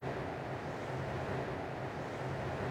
tunnelRight.wav